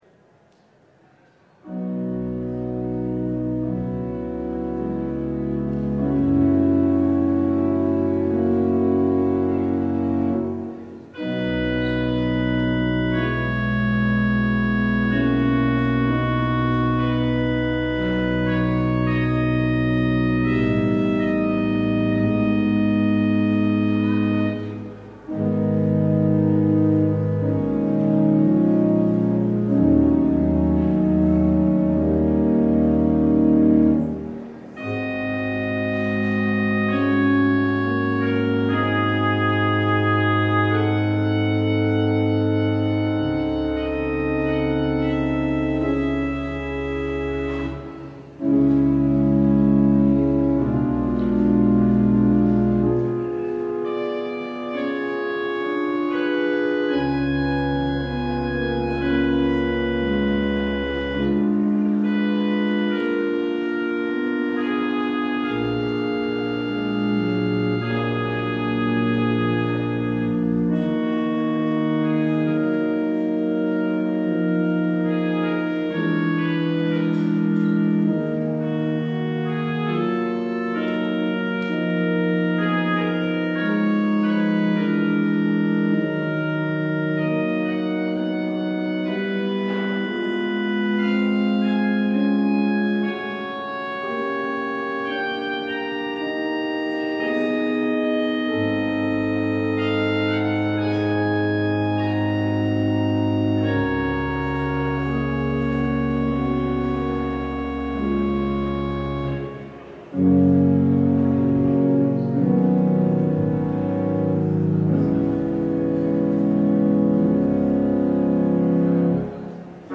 LIVE Midday Worship Service - The Image of the Invisible God: Compassion
Congregational singing—of both traditional hymns and newer ones—is typically supported by our pipe organ.